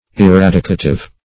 Eradicative \E*rad"i*ca*tive\, n. (Med.)